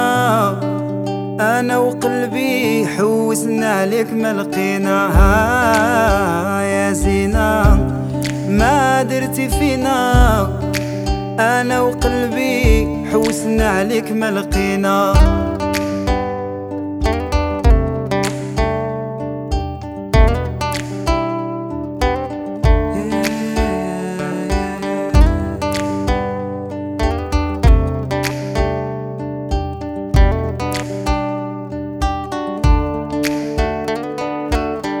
# North African